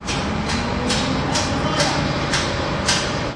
hammering » hammering12
描述：Sounds of someone on a construction site using a hammer. Unprocessed field recording.
标签： environmentalsoundsresearch building construction fieldrecording hammer
声道立体声